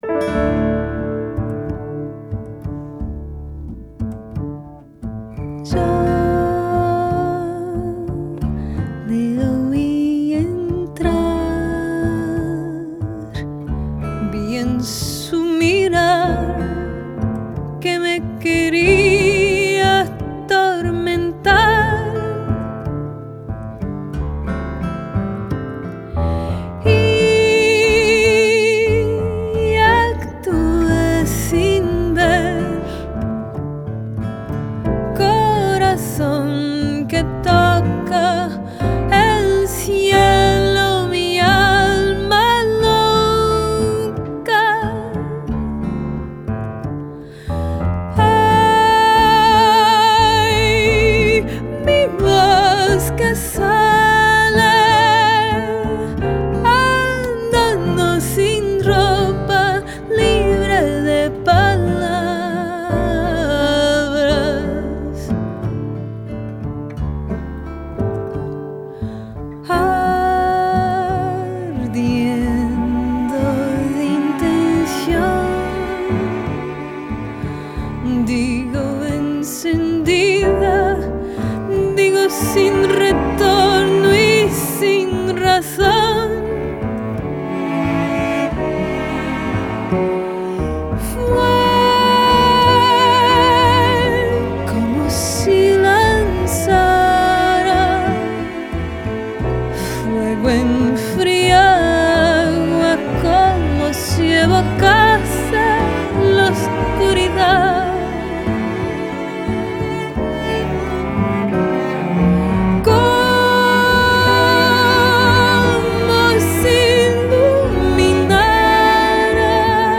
Portuguese Guitar
Double bass
Accordion